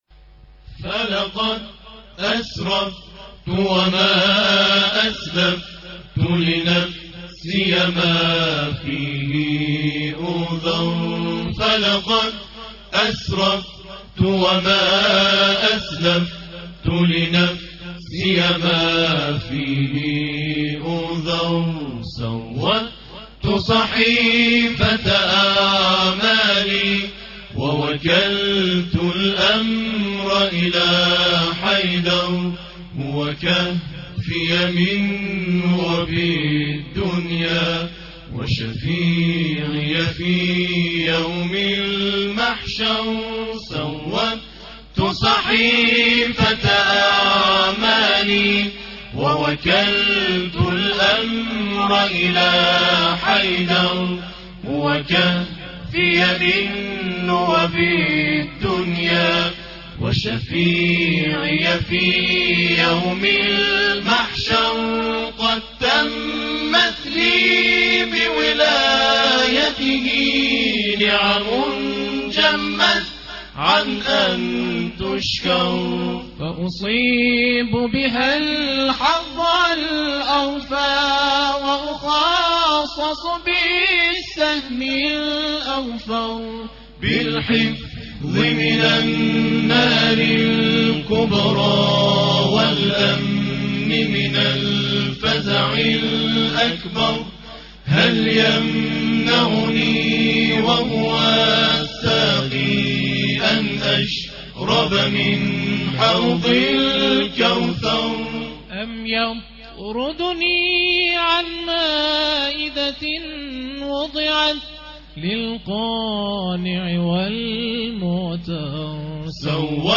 نوزدهمین دوره مسابقات سراسری مدیحه‌سرایی و همخوانی قرآن کریم در بخش آقایان 20 شهریورماه در مجتمع فرهنگی شهدای انقلاب اسلامی سرچشمه با رقابت 14 گروه مدیحه‌سرایی برگزار شد.
تواشیح
تواشیح-گروه-طه-مازندران.mp3